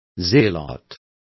Complete with pronunciation of the translation of zealot.